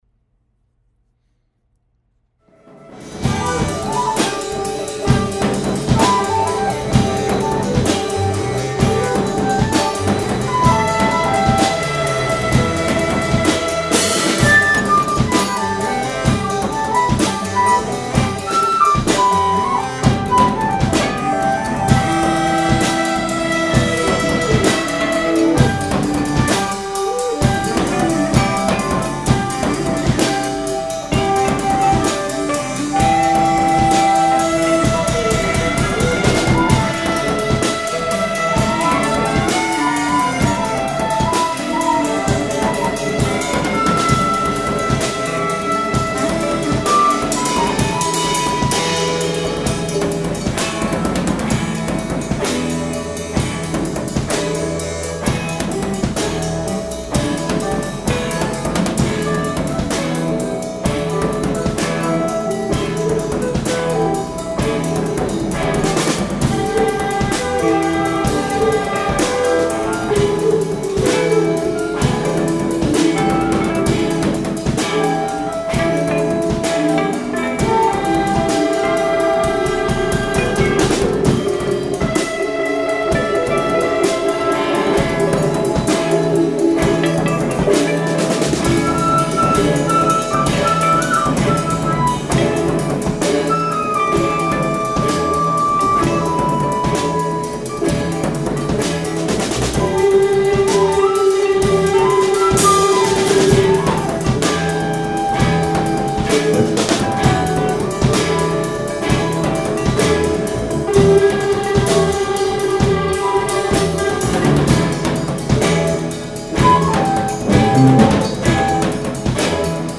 bass/moog/keys
guitar
flute
sitar
all music improvised on site with minimal editing